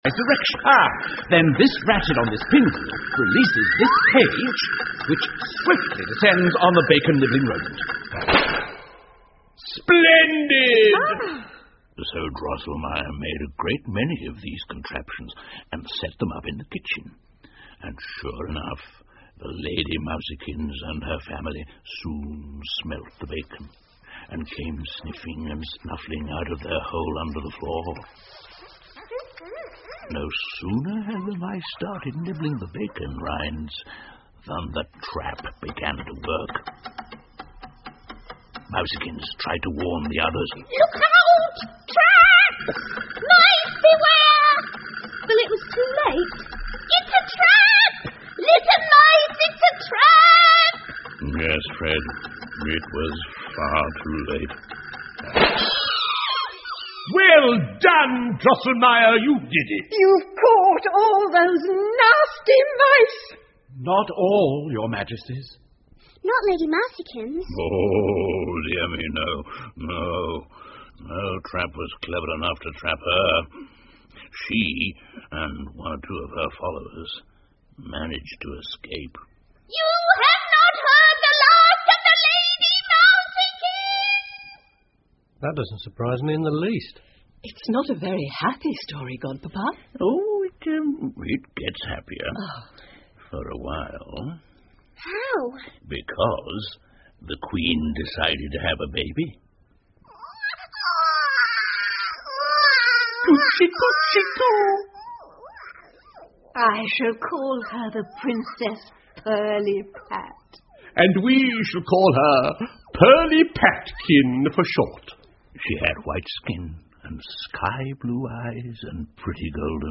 胡桃夹子和老鼠国王 The Nutcracker and the Mouse King 儿童广播剧 17 听力文件下载—在线英语听力室